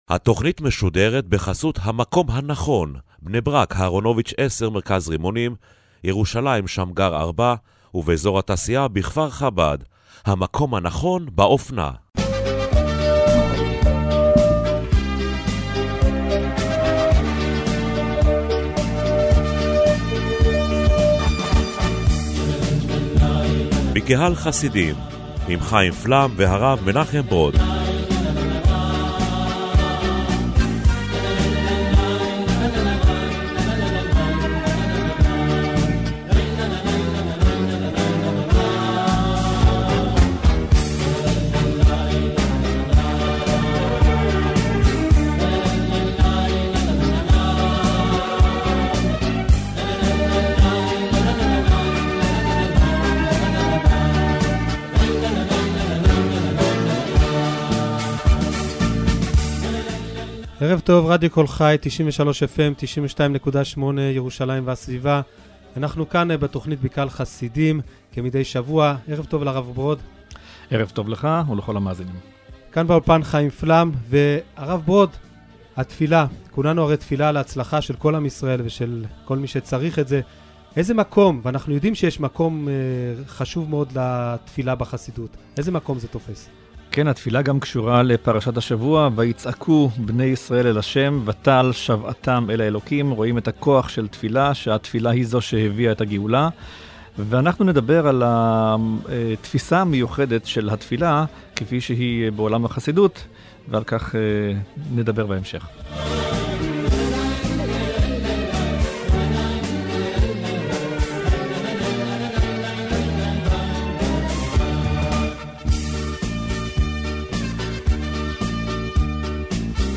הנושא המרכזי בתכנית היה: התפילה על פי החסידות, וגם חמישה דברים שלא ידעתם על חסידות מודזיץ. התכנית משודרת ברדיו 'קול חי', מדי יום חמישי בשעה 6 בערב